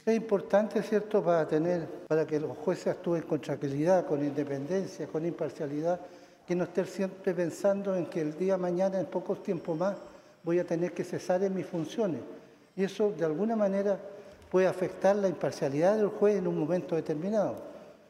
El presidente de la Corte Suprema, Juan Eduardo Fuentes Belmar, inauguró el año judicial 2022 y rindió la cuenta pública de la institución.